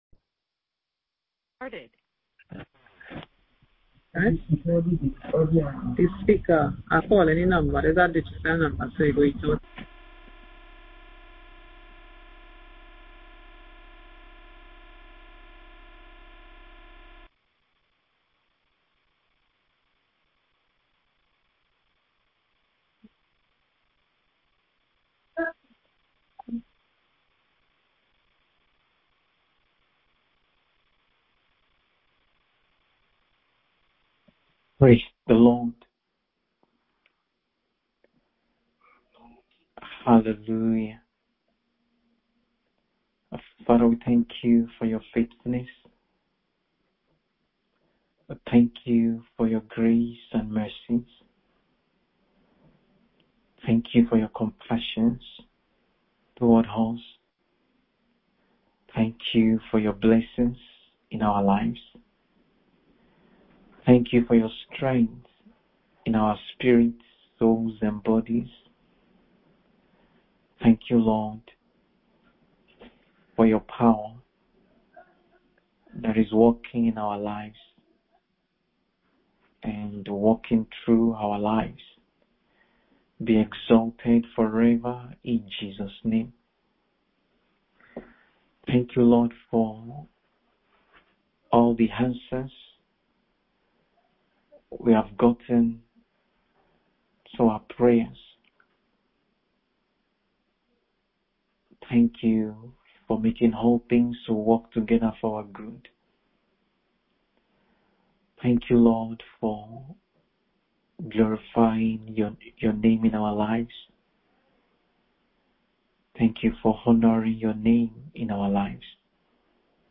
MIDNIGHT PRAYER SESSION : 28 NOVEMBER 2024